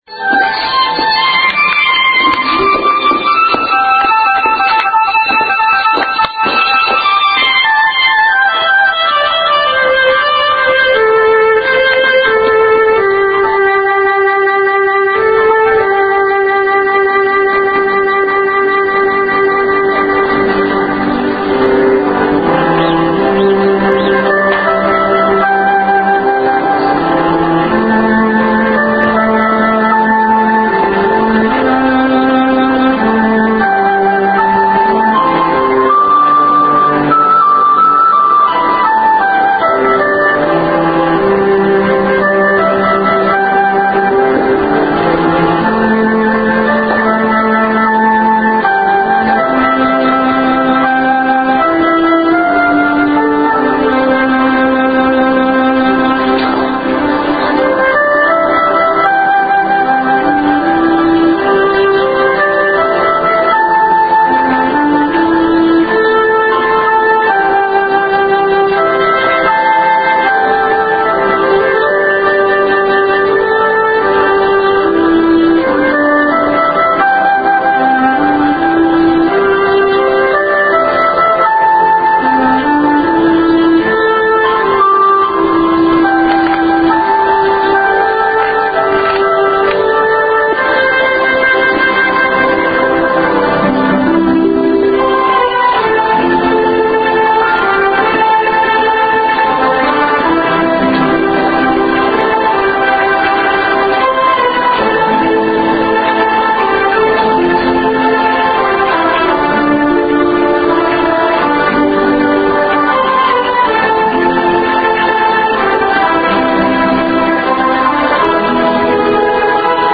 正在播放：--主日恩膏聚会录音（2014-09-28）